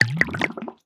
Minecraft Version Minecraft Version 1.21.5 Latest Release | Latest Snapshot 1.21.5 / assets / minecraft / sounds / block / brewing_stand / brew2.ogg Compare With Compare With Latest Release | Latest Snapshot
brew2.ogg